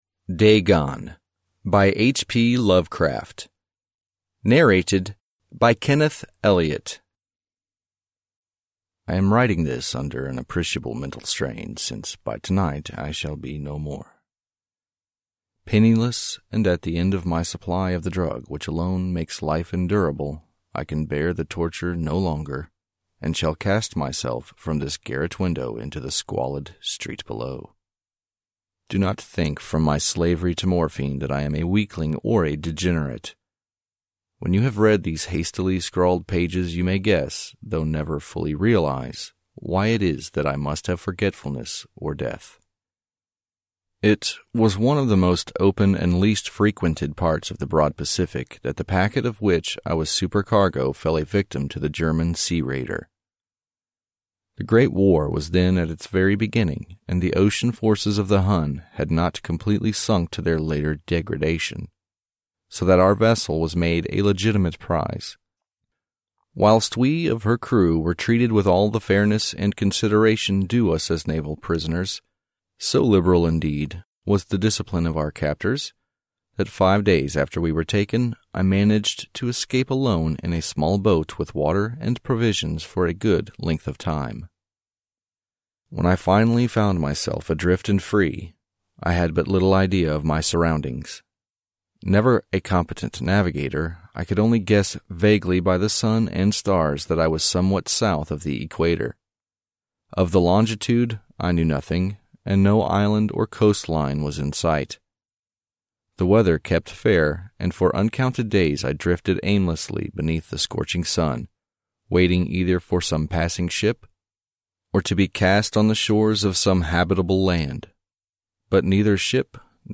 Аудиокнига Necronomicon | Библиотека аудиокниг
Прослушать и бесплатно скачать фрагмент аудиокниги